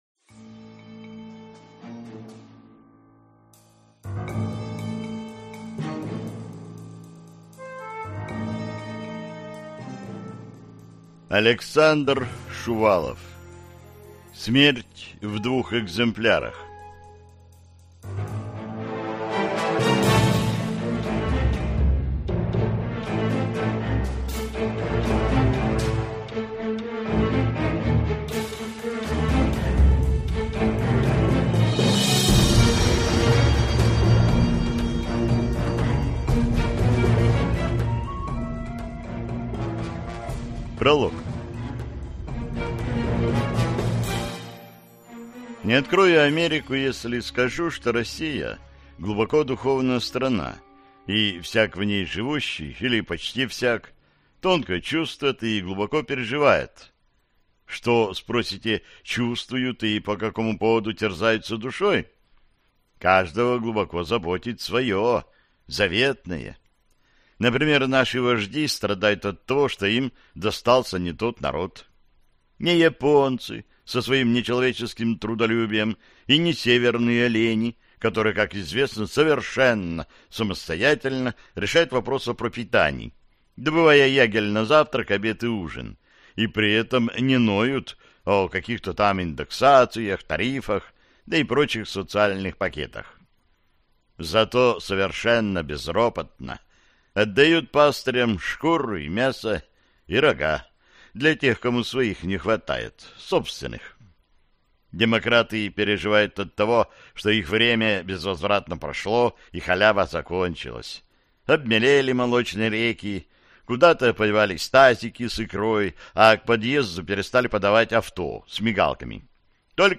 Аудиокнига Смерть в двух экземплярах | Библиотека аудиокниг